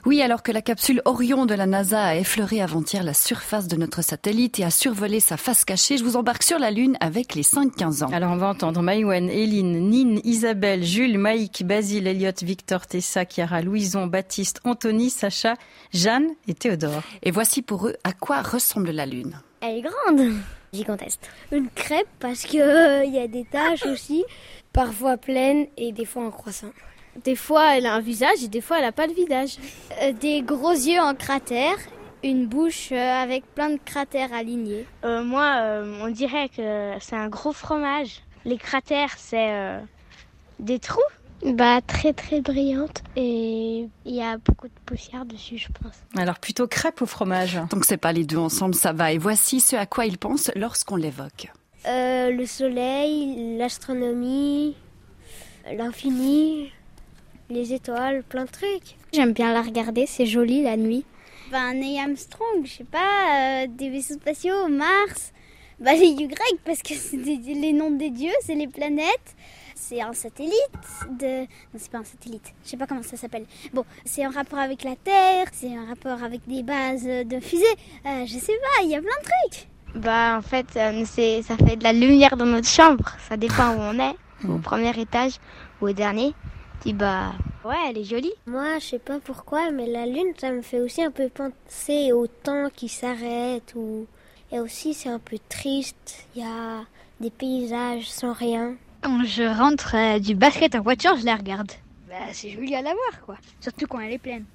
Emission sur la RTS La Première consacrée à la Lune